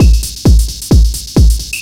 DS 132-BPM A5.wav